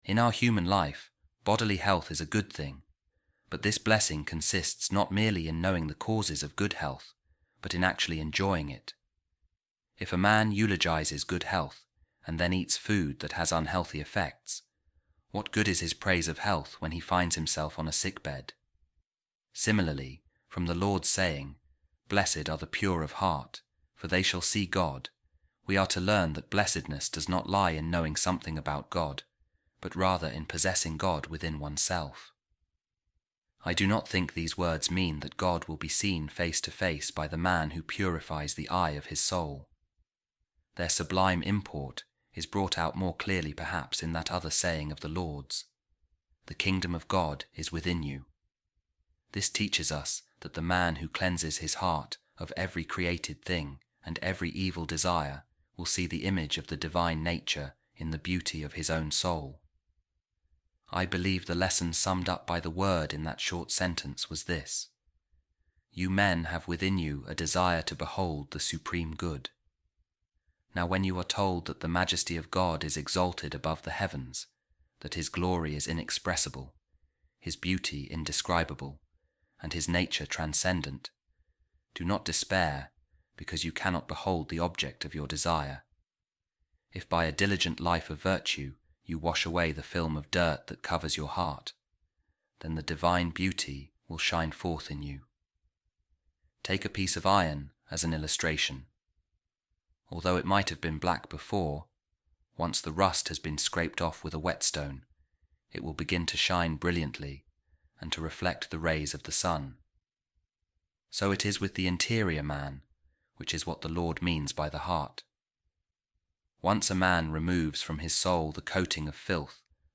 A Reading From The Homilies Of Saint Gregory Of Nyssa On The Beatitudes | God Can Be Found In Man’s Heart